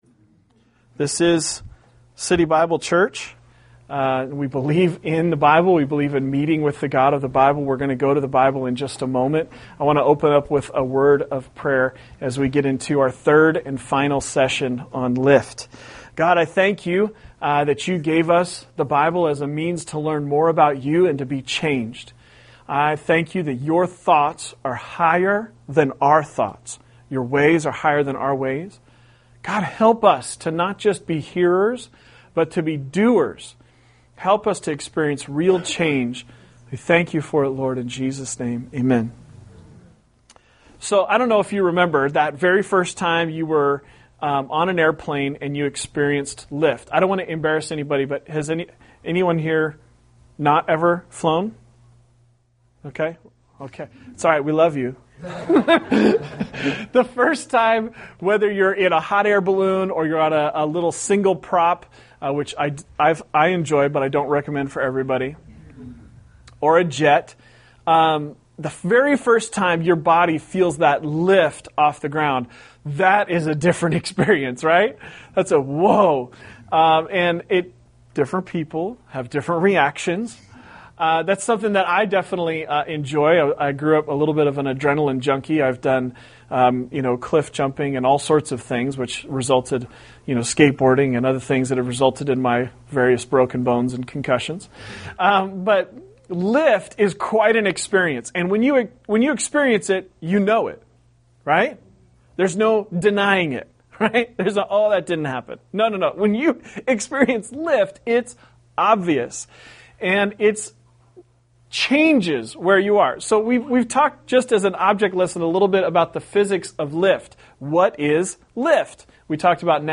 An Exhortation on Psalm 139 » City Harbor Church - Hampden, Baltimore, MD